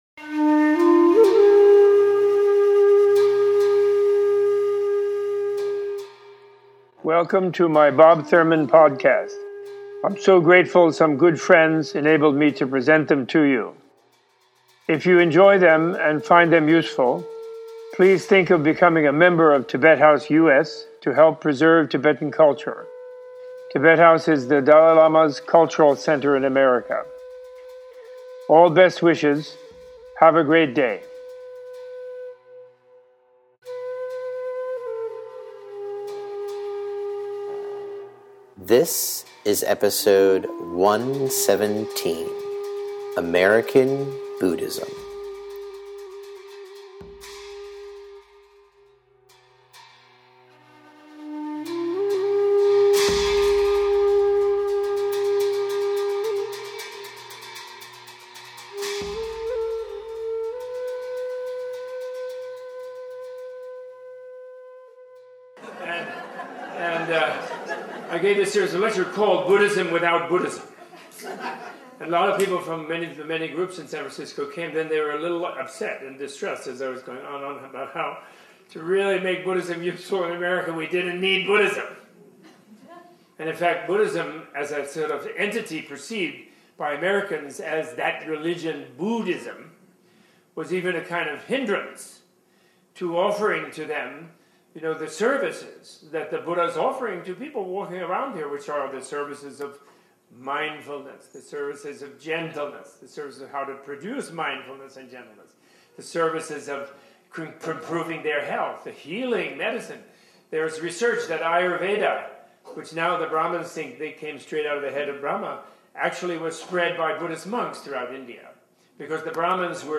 Recorded at the Awakened Leadership Conference hosted by the Frederick P. Lenz Foundation in 2009.